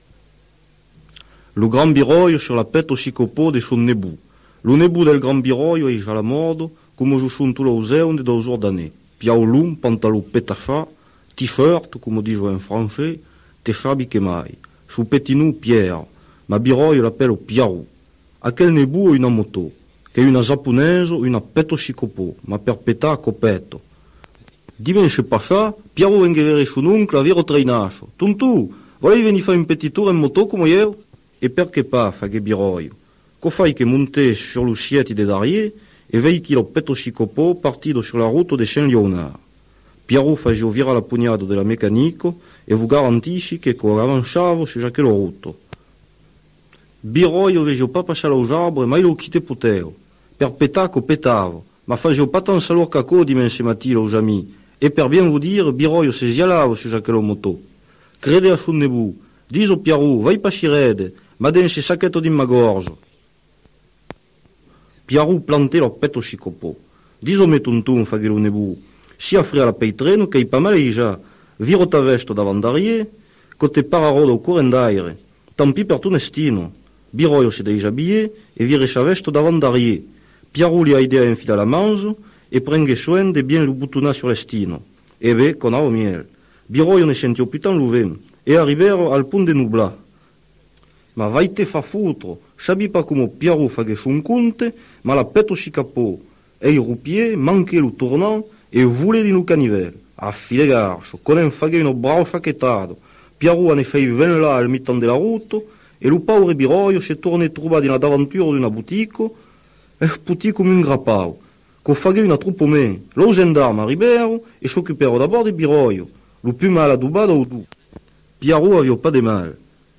(accent du Pays bas-limousin)